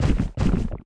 drop_1.wav